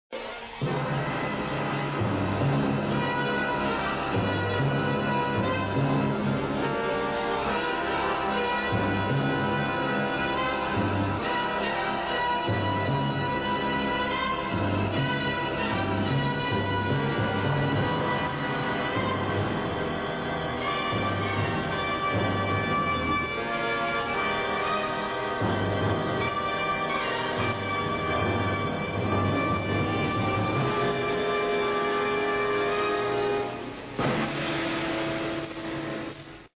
The third series, Quatermass and the Pit (1959) was a dark, cabalistic tale of the excavation of an ancient alien-insect capsule in Knightsbridge, London from which an evil influence still exudes. The mood-drenched drama excelled in eerie sound and visual effects.